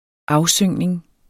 Udtale [ -ˌsøŋˀnəŋ ]